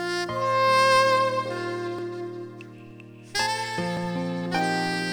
ADPCM sound G721 - guitar 10s G723 - guitar 10s G726 - guitar 10s WAV - guitar 10s G721 - Piano 20s G723 - Piano 20s G726 - Piano 20s WAV - Piano 20s G721 - Sax 5s G723 - Sax 5s G726 - Sax 5s WAV - Sax 5s
Sax.wav